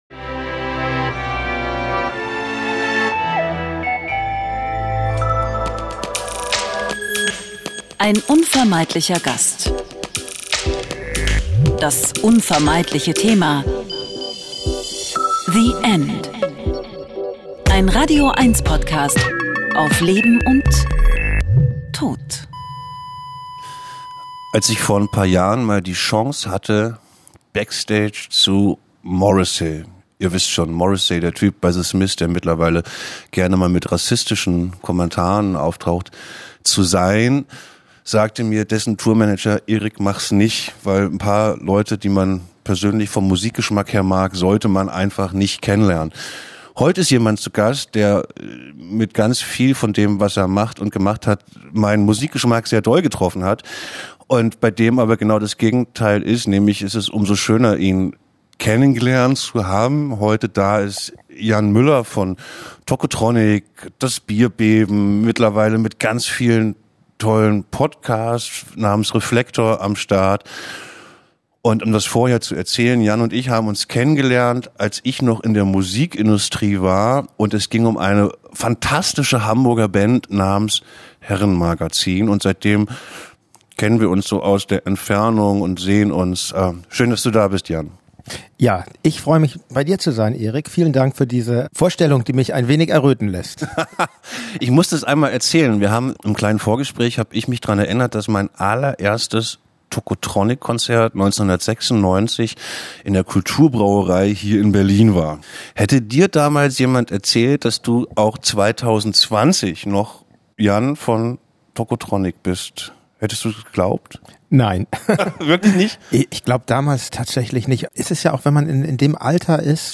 Ein Gespräch über Vergänglichkeit und Sterbebegleitung, aber auch über Musik und das Weiterleben.